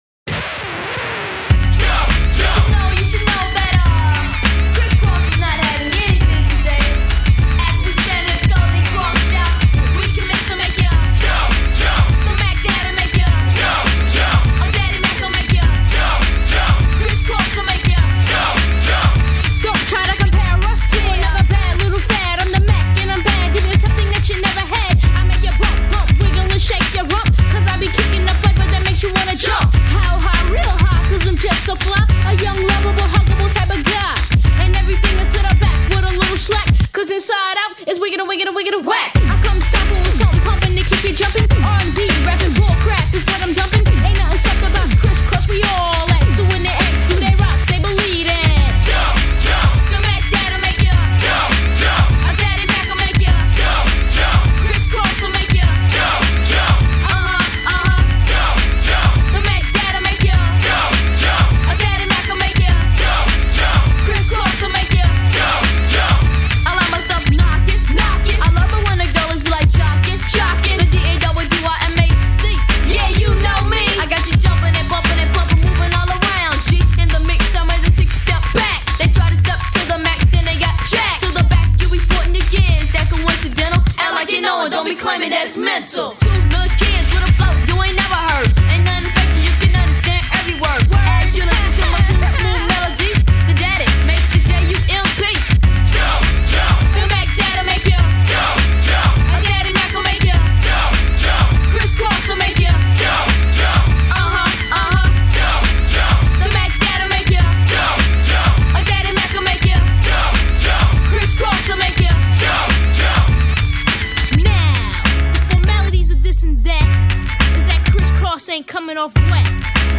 Категория: Зарубежный рэп, хип-хоп